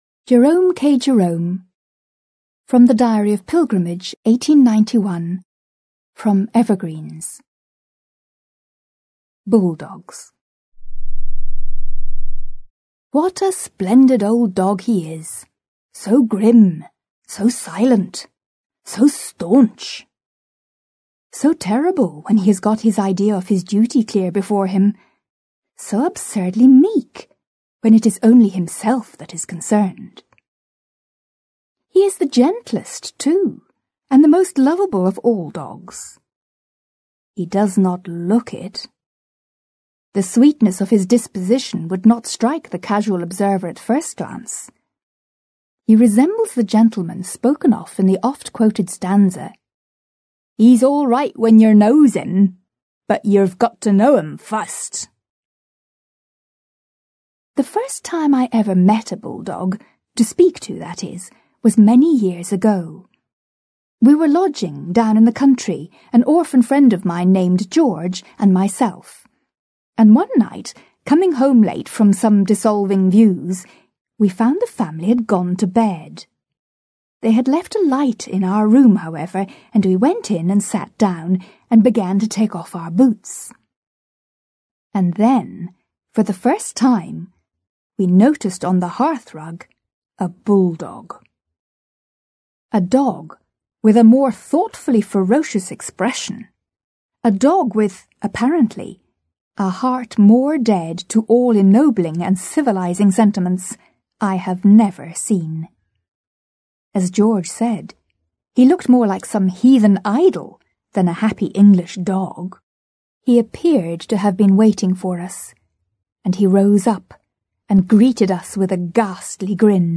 Аудиокнига Classic english stories | Библиотека аудиокниг